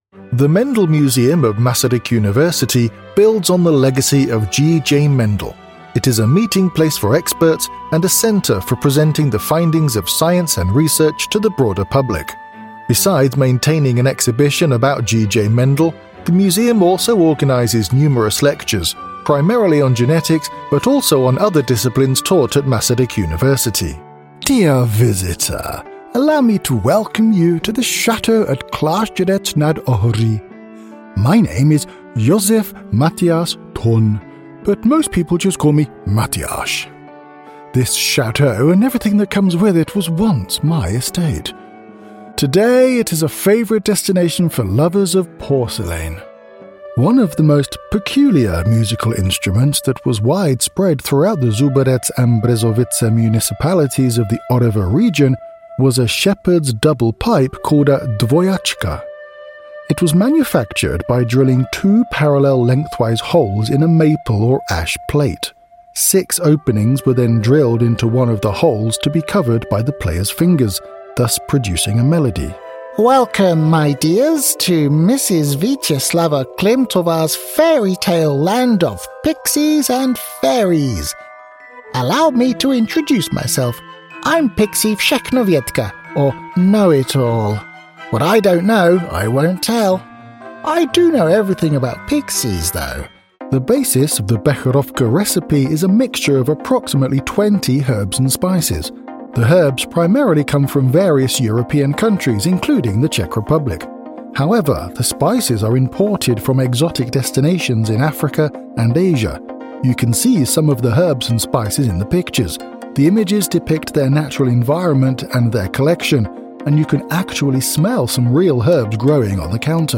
British male middle-aged voice over artist with studio for Corporate Narration, Audio Guides, e-Learning, Commercial, Characters.
britisch
Sprechprobe: Sonstiges (Muttersprache):
British male voice over here to take the weight off your shoulders and give you the freedom to be creative.